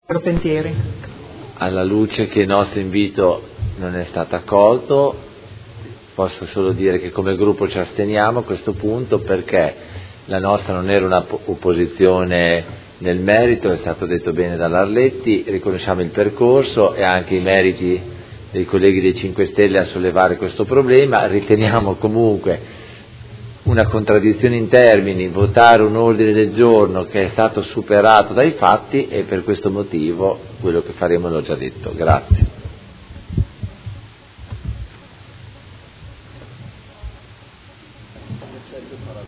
Seduta del 27/04/2017 Dibattito. Ordine del Giorno presentato dal Gruppo Movimento 5 Stelle avente per oggetto: Fondi per la sicurezza dei ciclisti